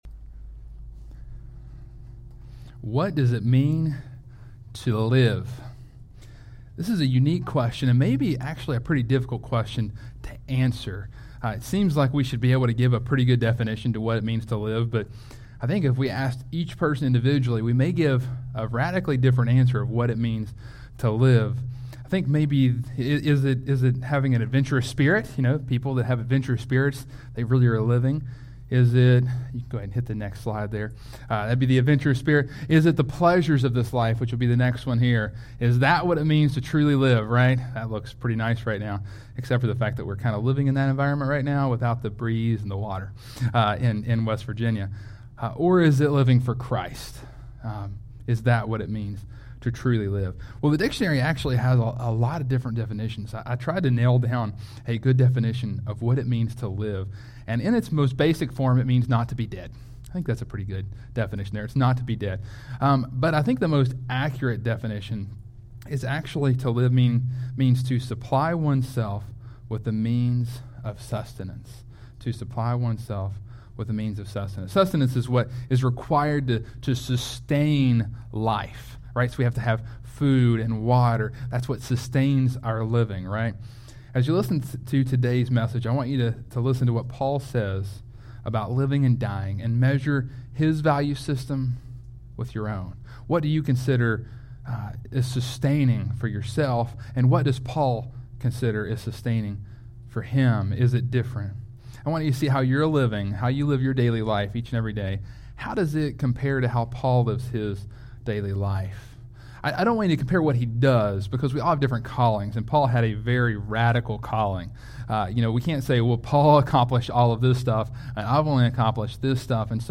Sermons | CrossPointe Family Church